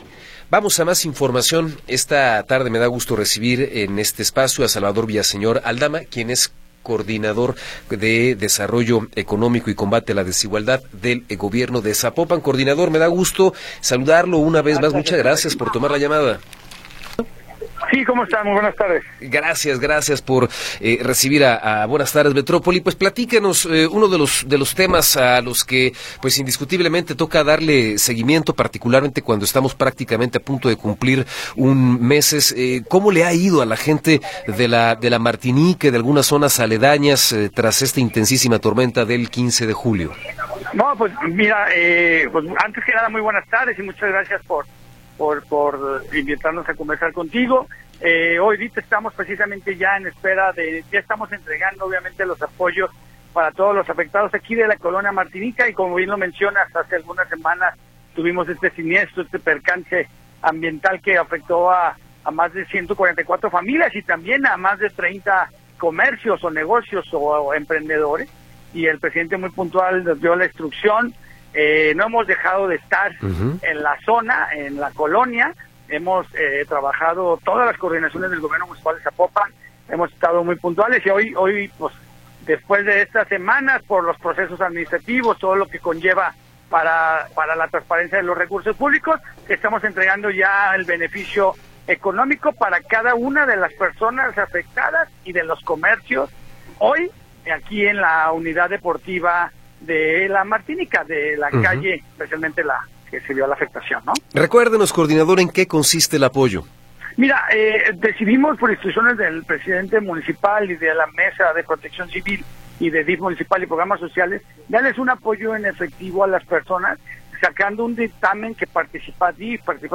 Entrevista con Salvador Villaseñor Aldama